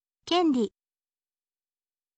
kenri